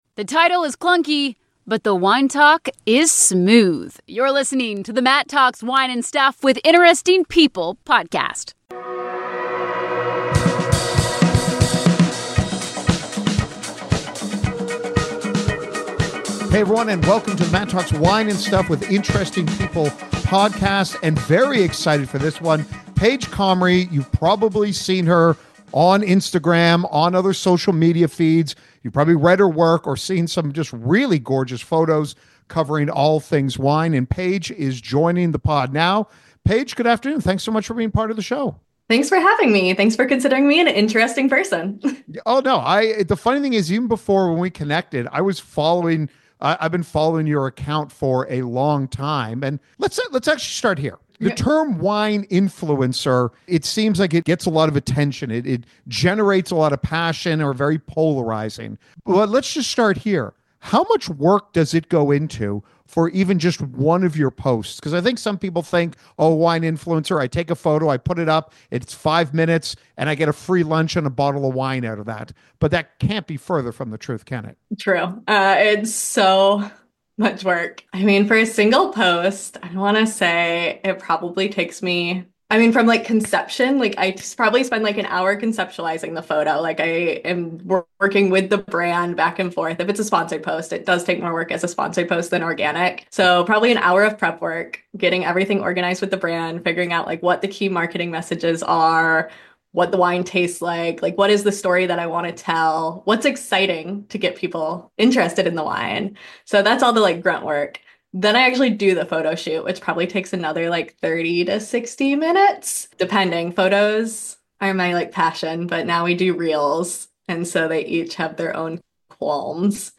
We discuss her big risk going from the world of business to going off on her own into the wide world of wine. A fun/thoughtful conversation with someone with a passion for communicating all the joy that comes from wine.